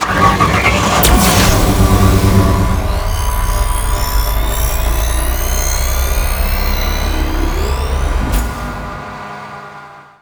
Cosmic Rage / vehicle